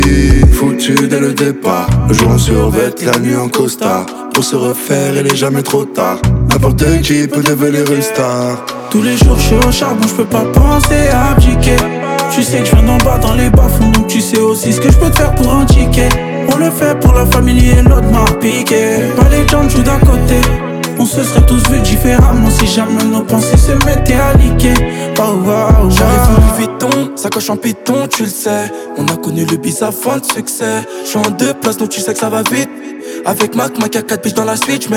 Жанр: Хип-Хоп / Рэп
Hip-Hop, Rap